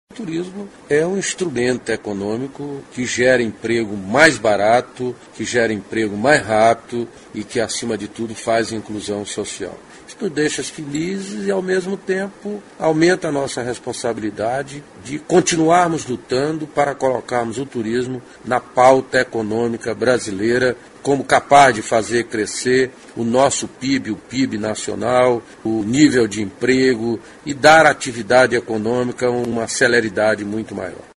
aqui e ouça declaração do ministro Gastão Vieira sobre a importância do turismo para a geração de emprego e renda.